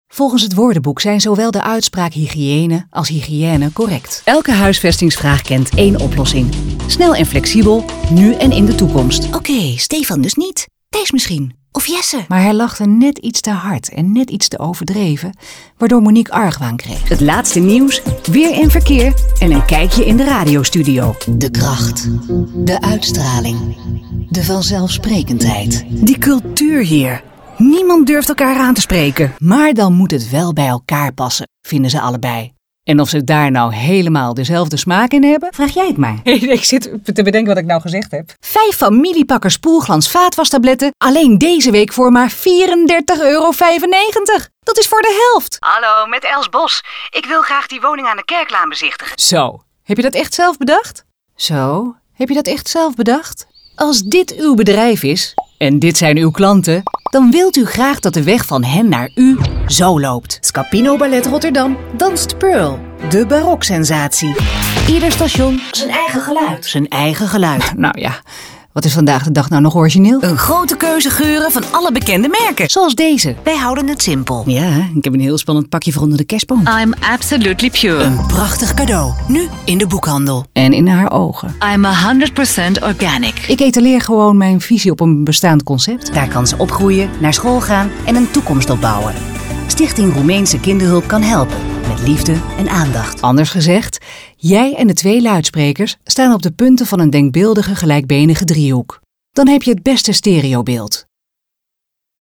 Haar communicatie stijl is nuchter, trefzeker, warm en betrokken; to-the-point.
Accentloos, helder en met de juiste toon voor jouw doelgroep.